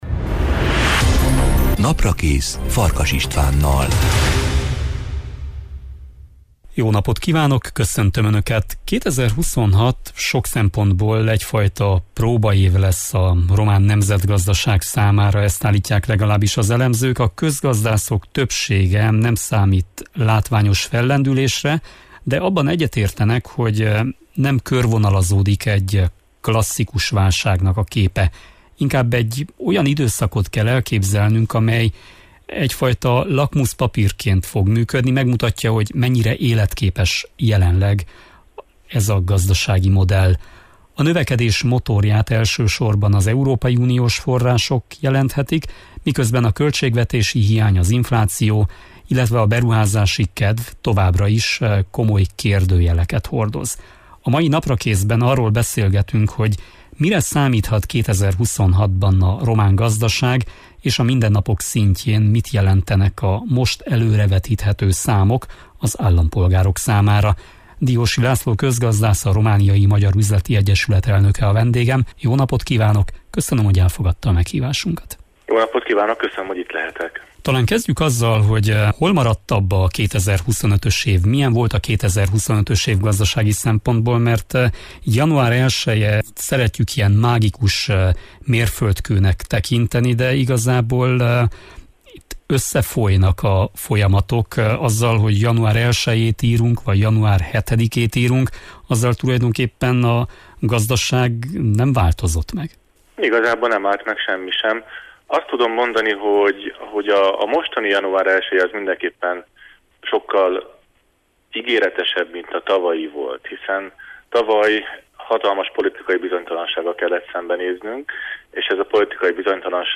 A növekedés motorját elsősorban az uniós források jelenthetik, miközben a költségvetési hiány, az infláció és a beruházási kedv továbbra is komoly kérdőjeleket hordoz. A mai Naprakészben arról beszélgetünk, hogy mire számíthat 2026-ban a román gazdaság, és a mindennapok szintjén mit jelentenek a most előrevetíthető számok az állampolgárok számára.